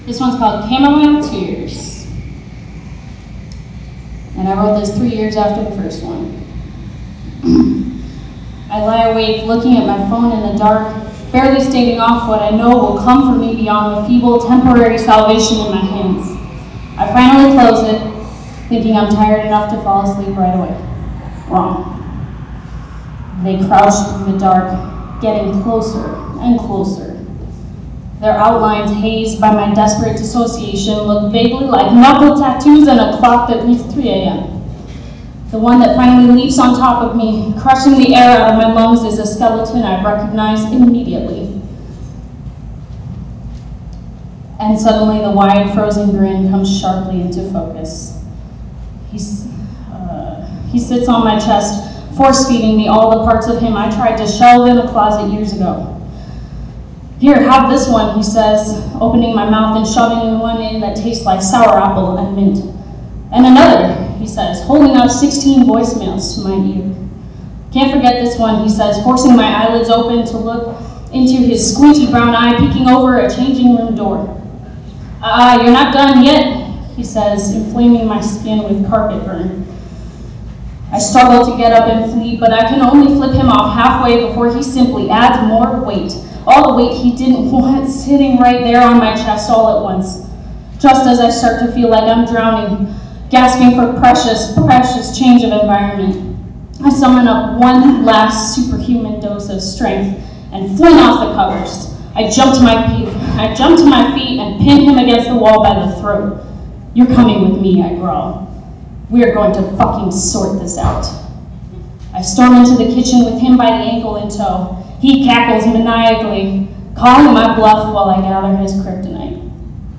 Poetry Slam - 10.08.17 Having It out with Melancholy ~ By Jane Kenyon